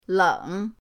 leng3.mp3